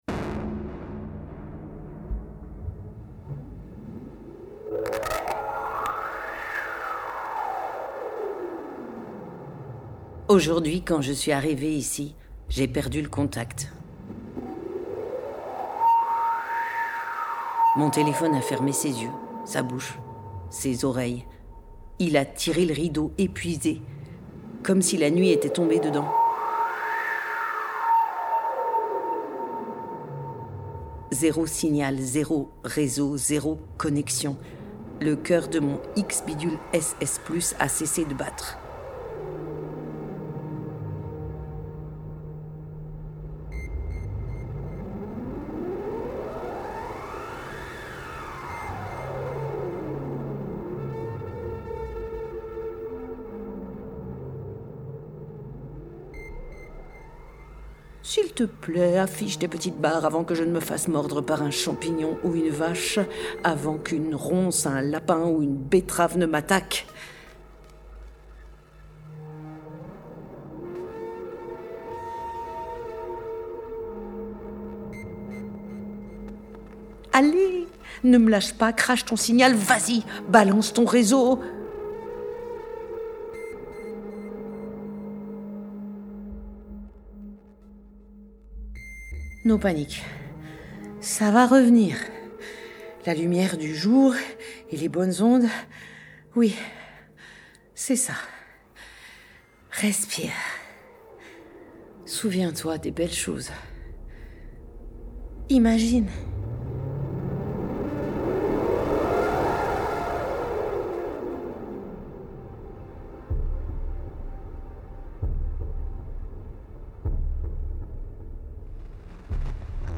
Mix-demo-Pop-CSPP.mp3